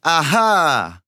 8 bits Elements
Voices Expressions Demo
Ah_ah_1.wav